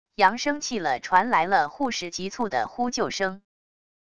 扬声器了传来了护士急促的呼救声wav音频